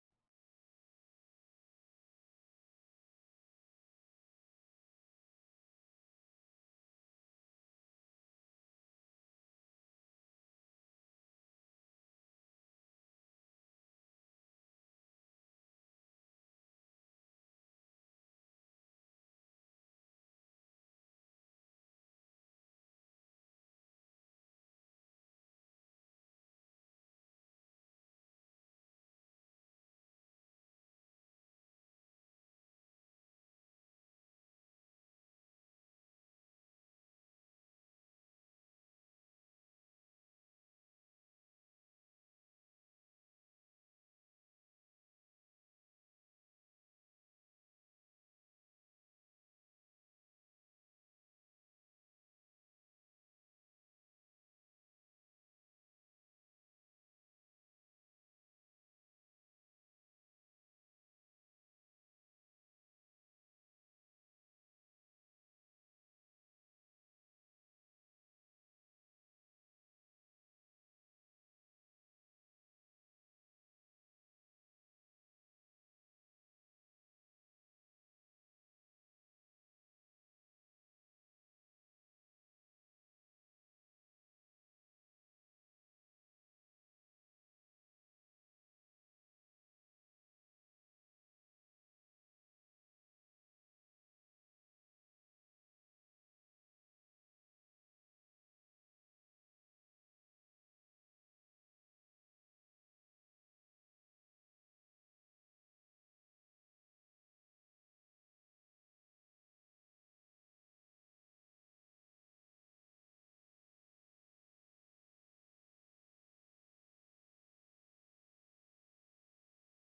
Recitation
Traducción al español del Sagrado Corán - Con Reciter Mishary Alafasi